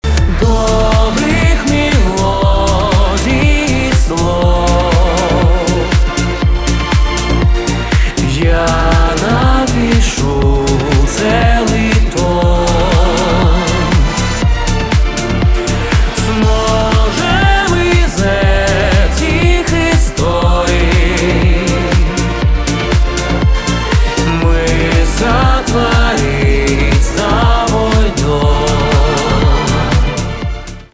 битовые , красивые , чувственные , скрипка , хор